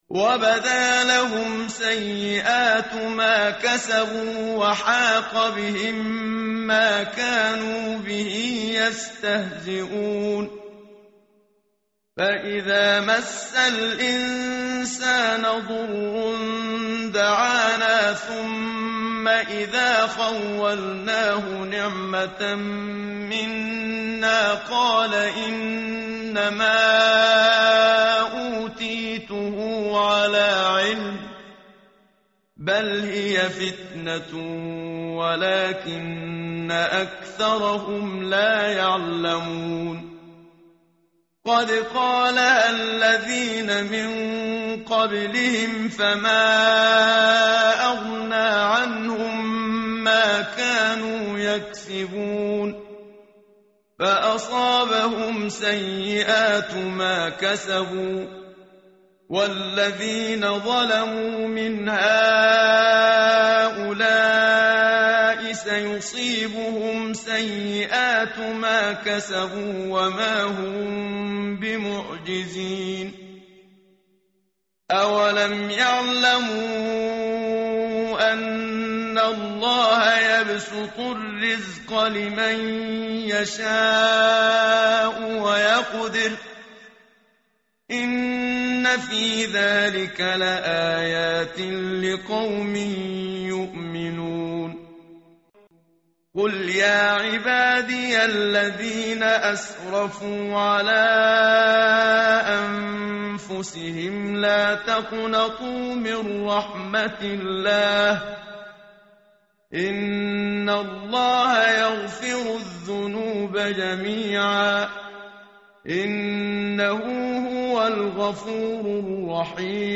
tartil_menshavi_page_464.mp3